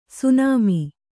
♪ sunāmi